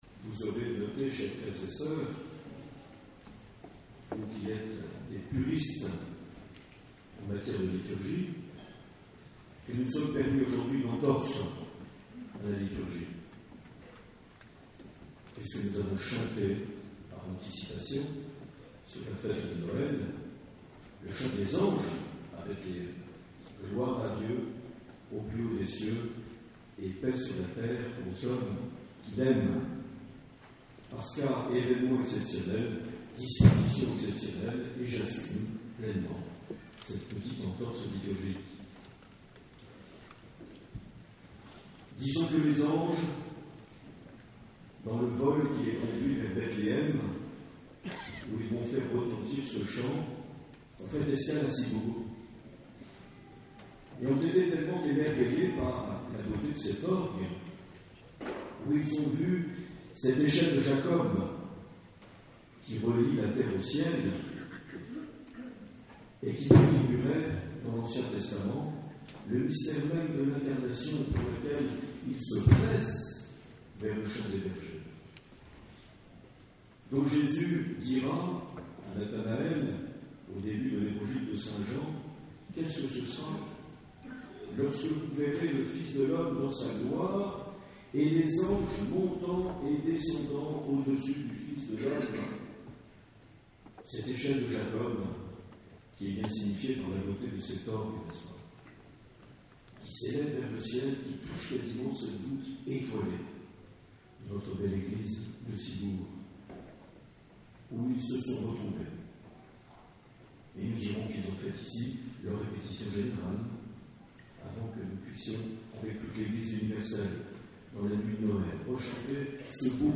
22 décembre 2013 - Ciboure Saint Vincent - Bénédiction du nouvel orgue
Accueil \ Emissions \ Vie de l’Eglise \ Evêque \ Les Homélies \ 22 décembre 2013 - Ciboure Saint Vincent - Bénédiction du nouvel (...)
Une émission présentée par Monseigneur Marc Aillet